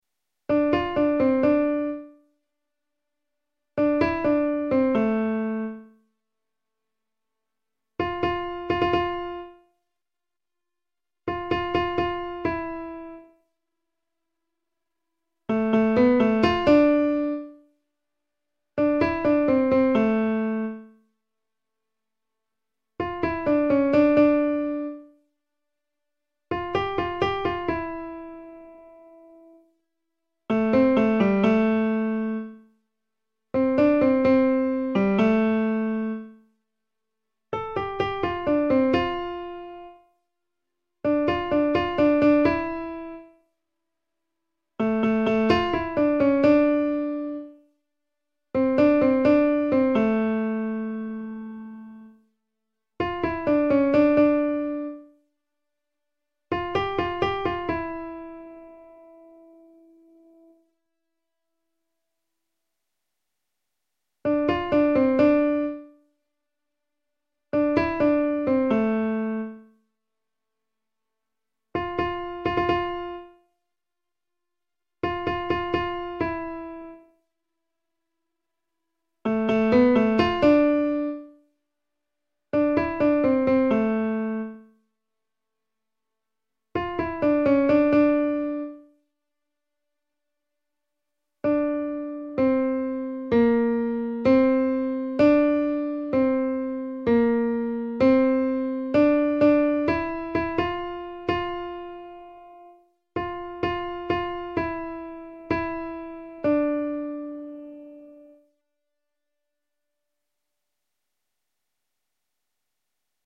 Übedateien
Alt MännerChor